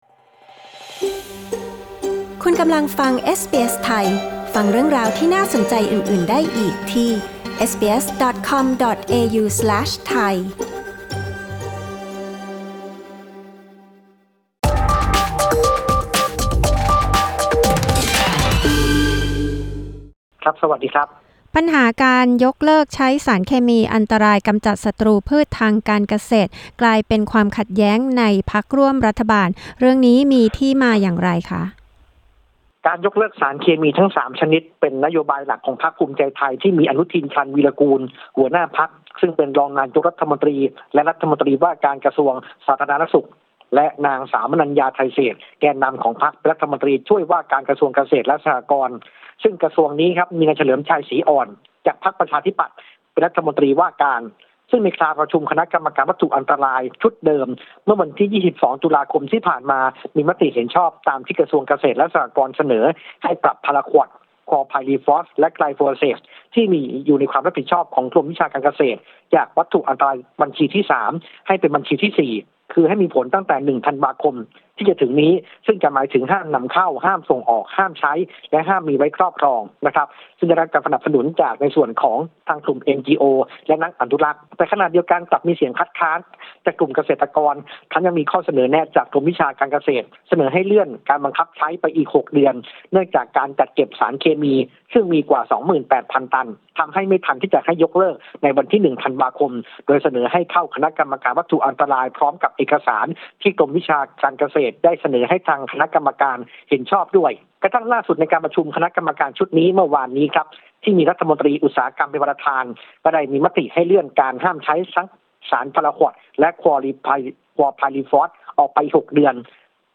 กดปุ่ม 🔊 ด้านบนเพื่อฟังรายงานข่าว